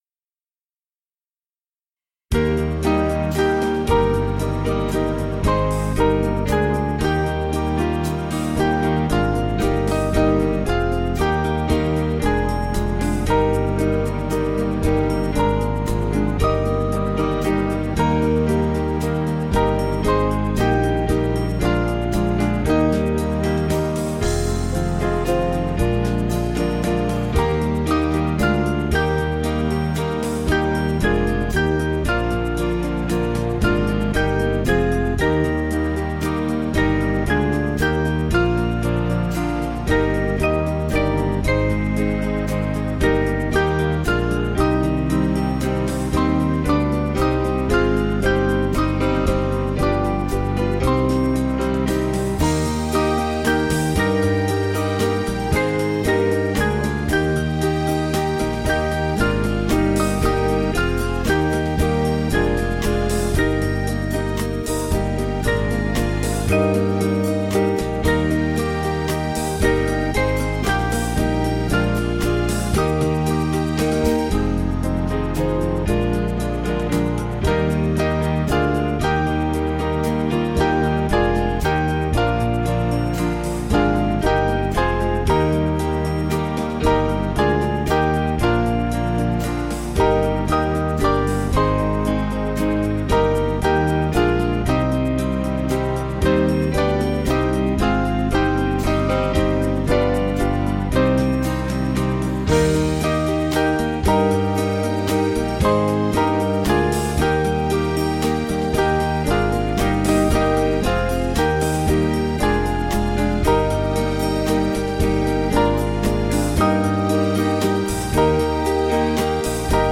Small Band
(CM)   5/Eb 487.5kb